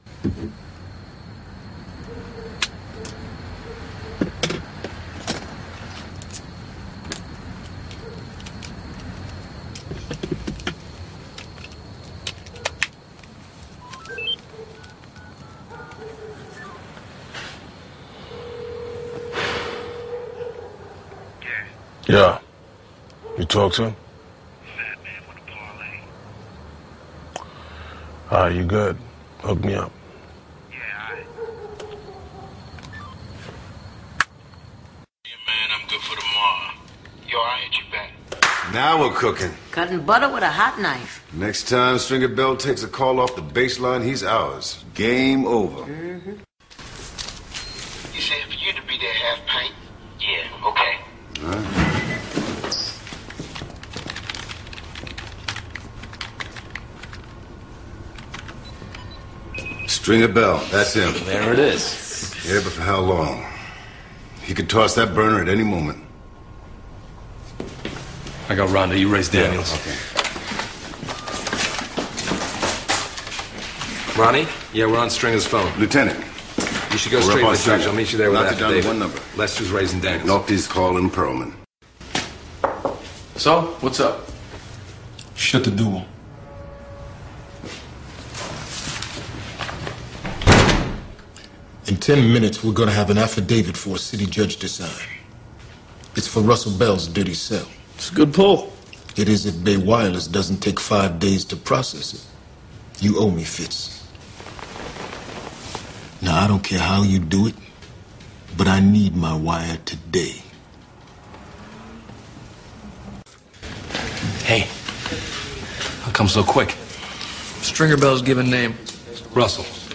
Cell phone sounds and stories and songs.